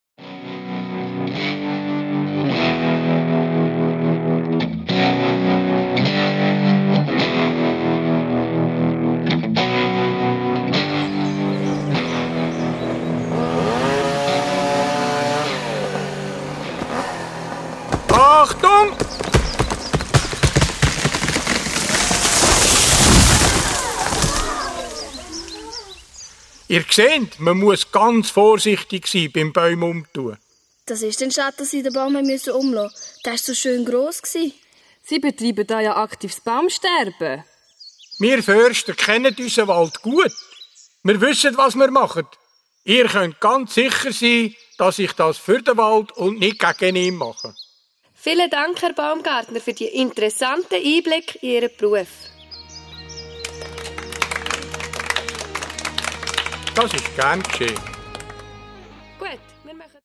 Hörspiel ab 8 Jahren